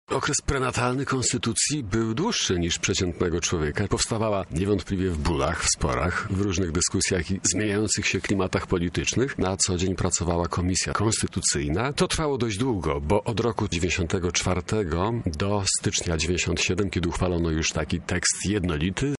Konstytucja kończy 18 lat. O jej narodzinach mówi konstytucjonalista